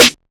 Snare - 90210.wav